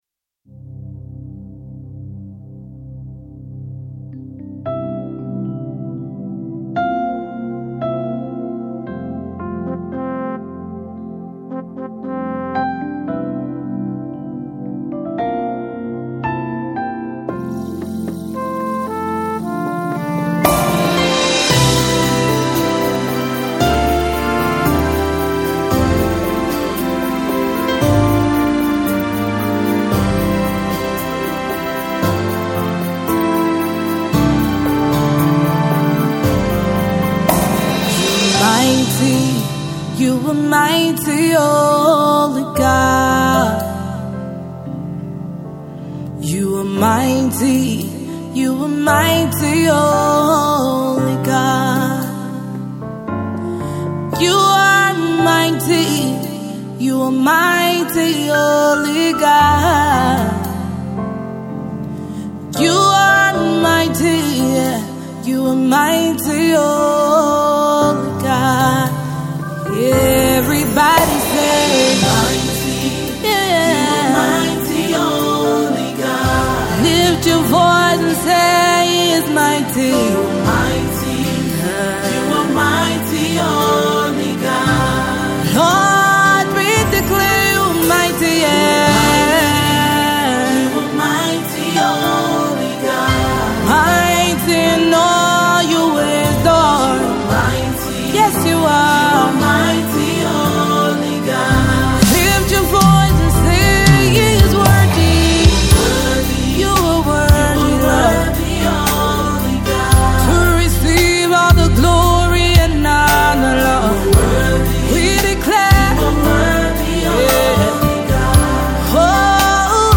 It is a love song to God as He is described by many names.